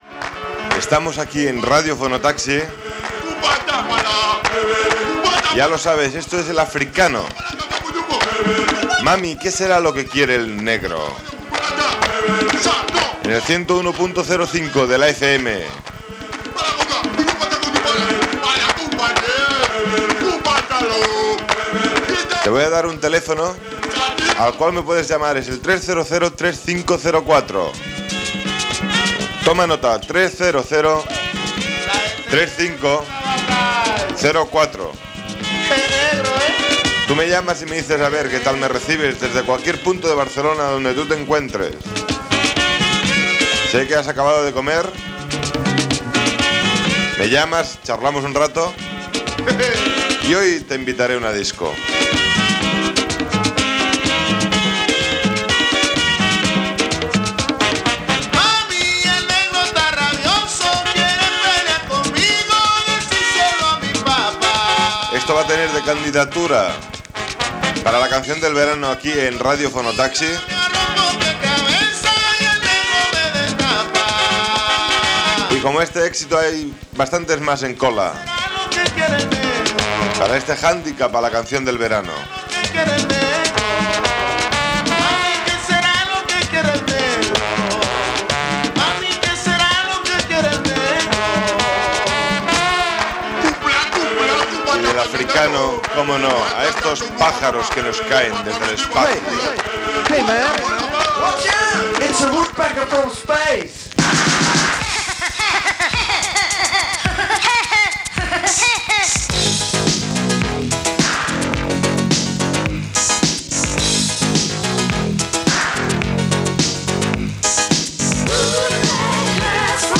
aa64c0aec2fff724ba0ee81125d4440d3b49a5f3.mp3 Títol Fono Taxi Emissora Fono Taxi Titularitat Tercer sector Tercer sector Musical Descripció Telèfon demanant controls d'escolta, identificació de l'emissora, hora i temes musicals.